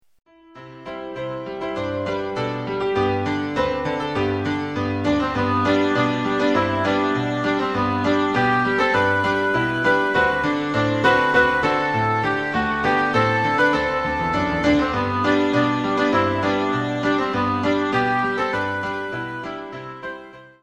Sample from the Rehearsal CD